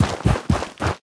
biter-walk-big-1.ogg